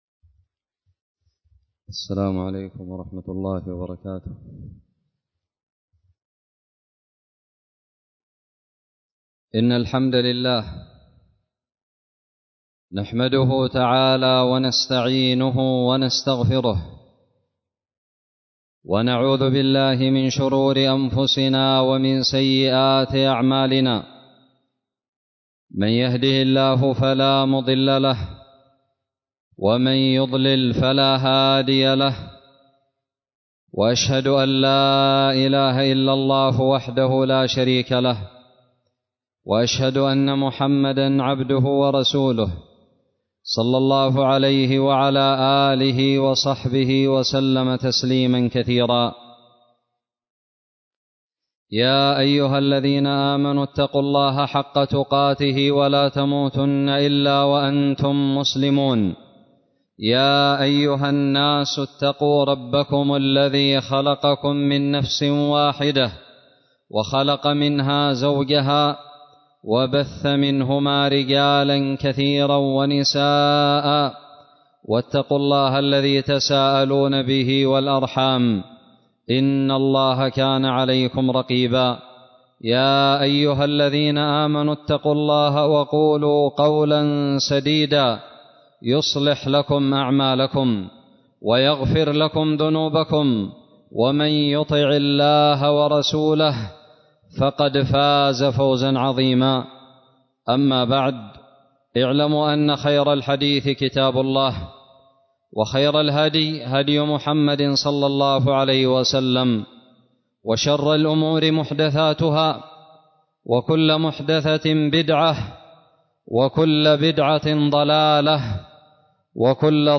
خطب الجمعة
ألقيت بدار الحديث السلفية للعلوم الشرعية بالضالع في 22 ذو القعدة 1442هـ